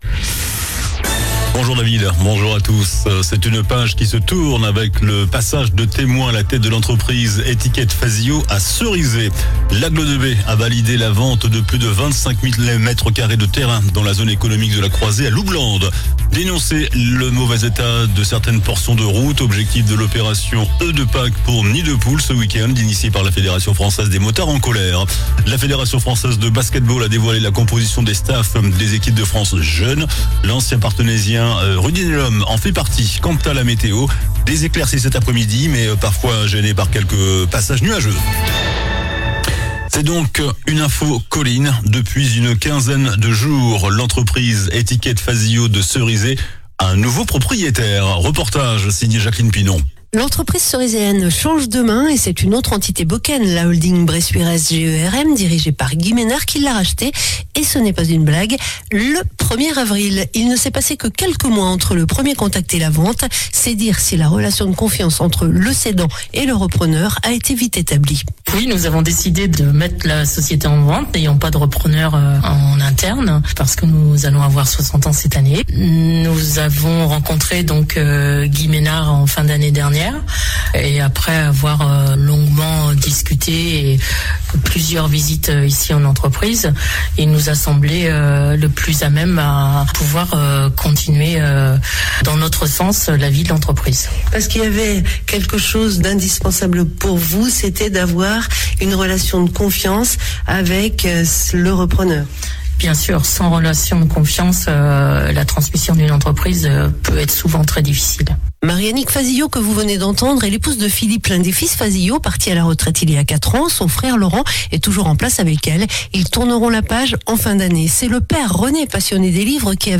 JOURNAL DU JEUDI 17 AVRIL ( MIDI )